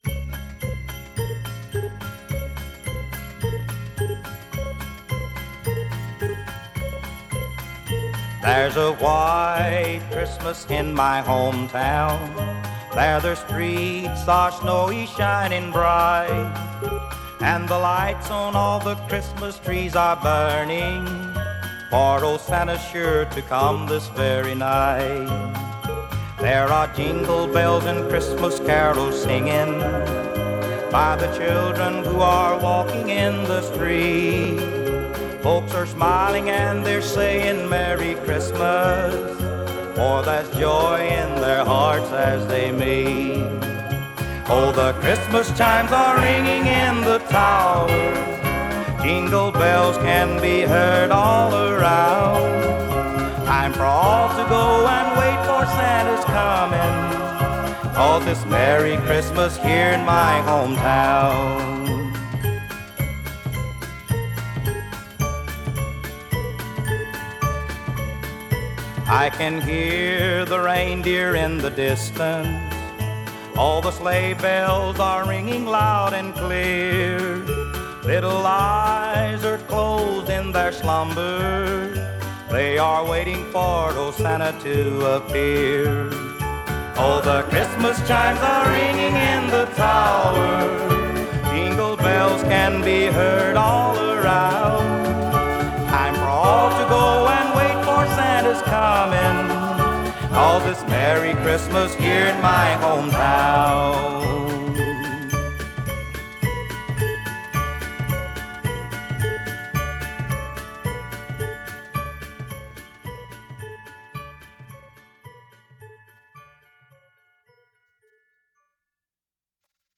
it has that "Nashville Sound"